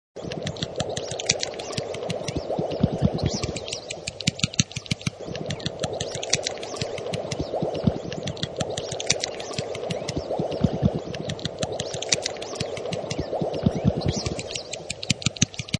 Biber
Wenn er seinen Bau bedroht sieht, beginnt der Biber lautstark zu fauchen und mit seinem Schwanz gegen die Wasseroberfläche zu klopfen. Bekannt sind die Biber vor allem für ihre starken Nagezähne, mit denen sie Bäume fällen.
biber.mp3